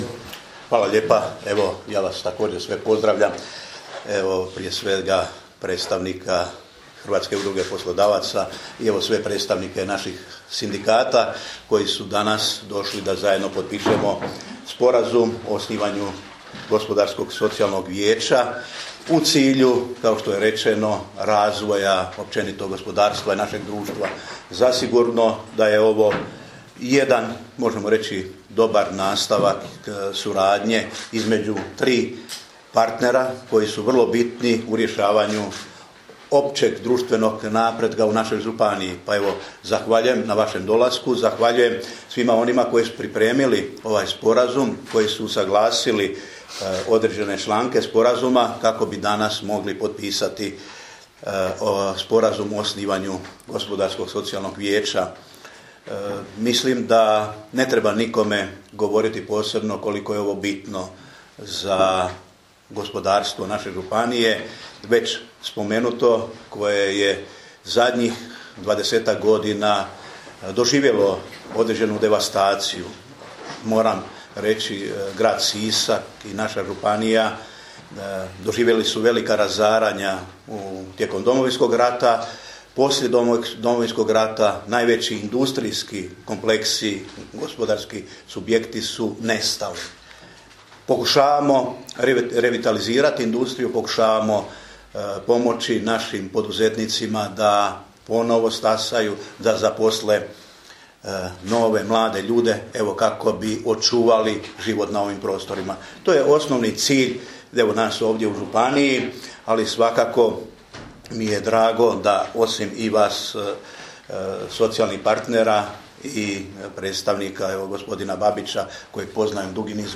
Ovdje poslušajte izjavu koju je župan Ivo Žinić dao u ovoj prigodi: